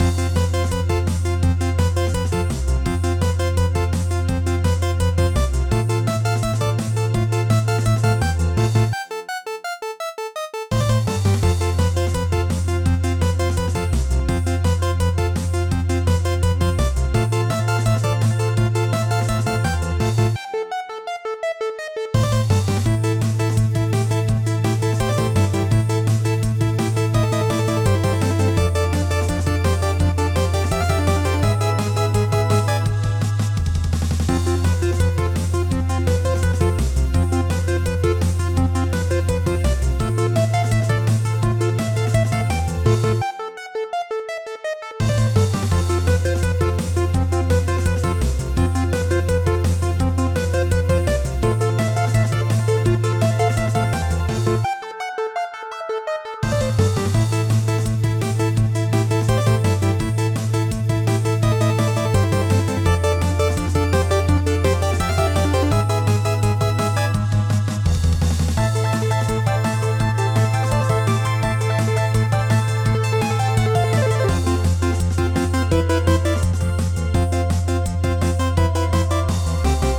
A high energy electronic bleep-bloopy beat for games where there's a lot of stuff going on!Includes an "intro" and "loop" section, a midi file with the main melody, and a short "menu" variation.